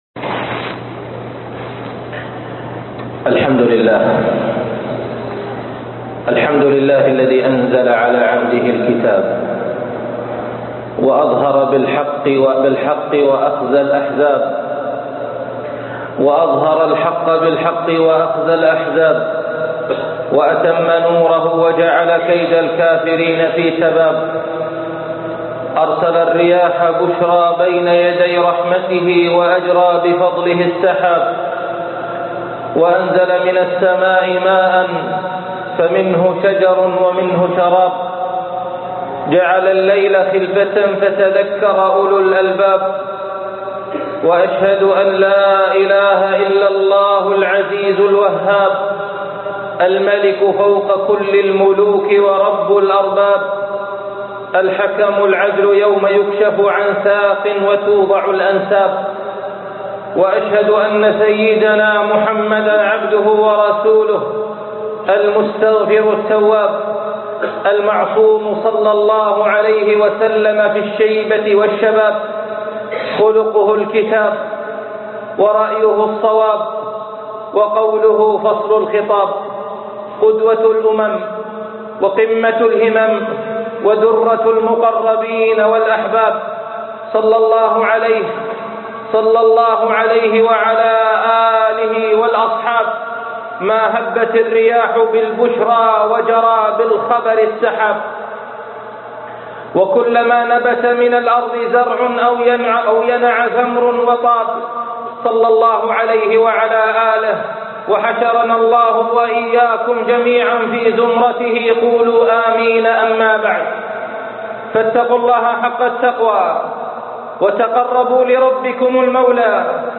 أخلاق الحروب - خطب الجمعة